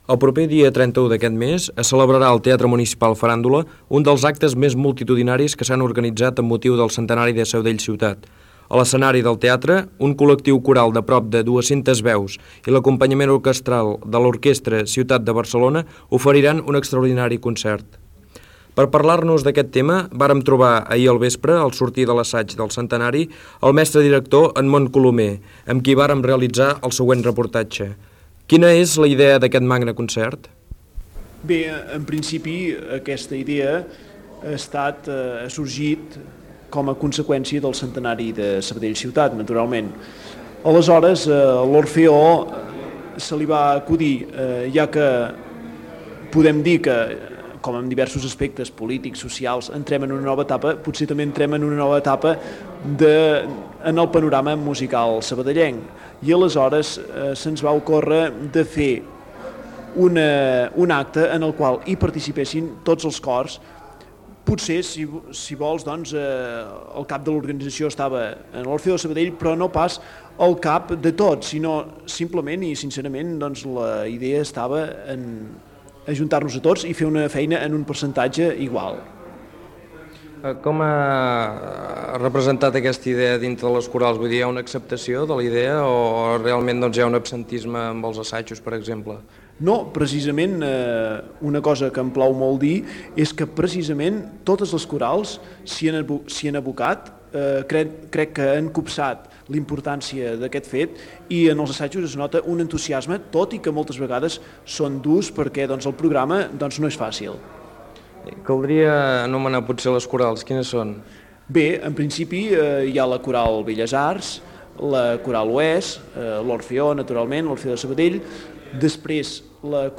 Entrevista al director d'orquestra Edmon Colomer sobre els assajos dels cors de Sabadell que cantaran en un dels actes que es faran pel Centenari Sabadell Ciutat